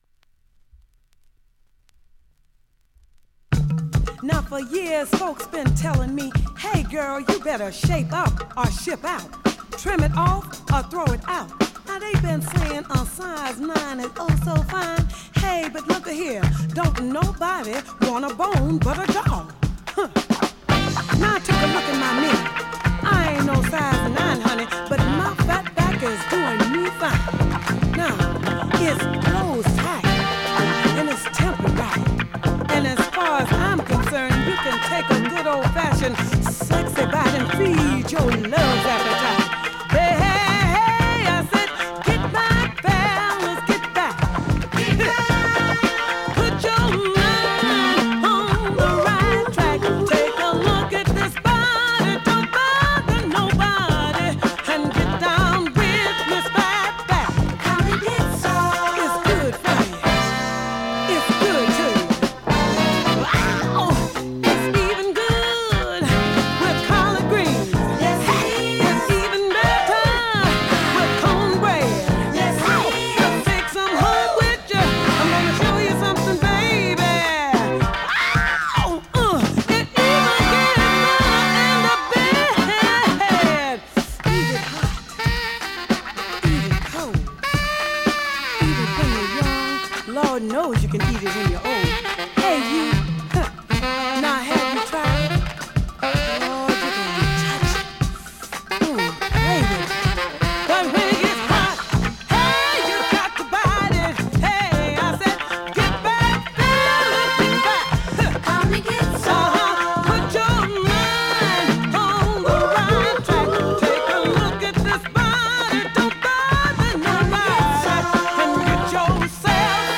現物の試聴（両面すべて録音時間６分４３秒）できます。